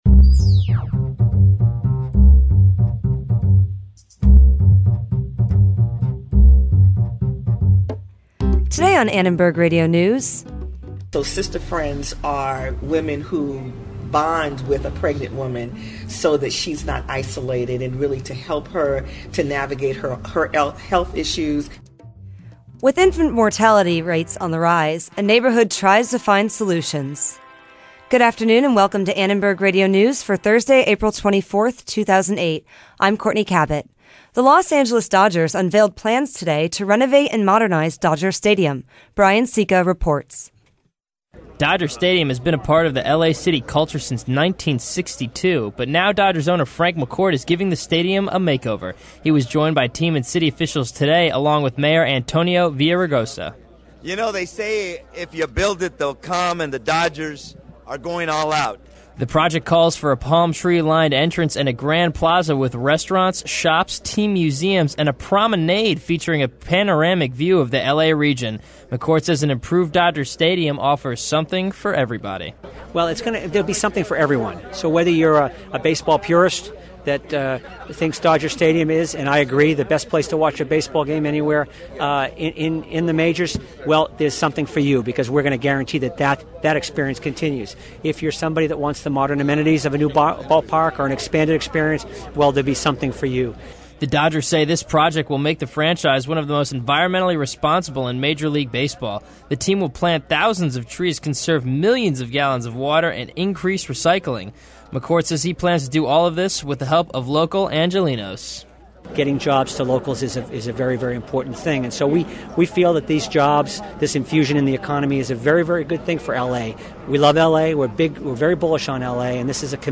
Protests, marches and rallies are marking the anniversary of the Armenian genocide. We went live from the Turkish consulate in Los Angeles where the Armenia Youth Federation is having their annual protest against the Turkish government's denial of the genocide.